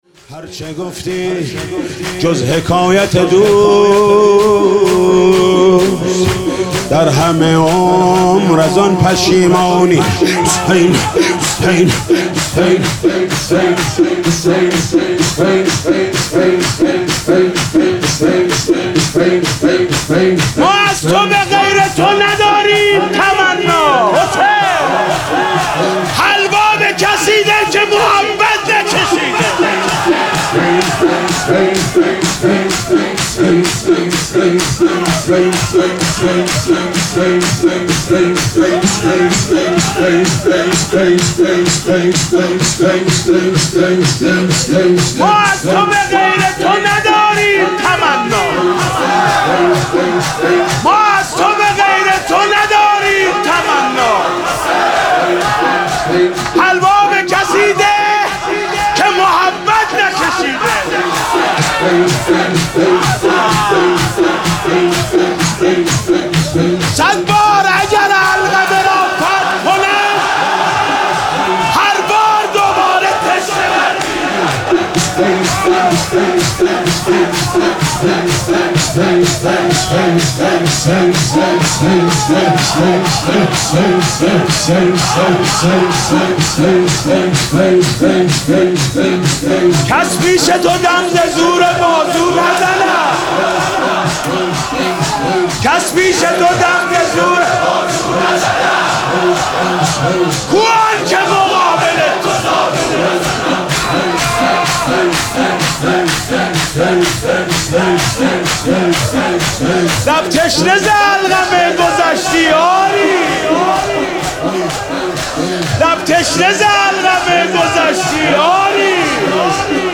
محرم 97 شب چهارم - کریمی - شور - هر چه گفتی جز حکایت دوست
حاج محمود کریمی 97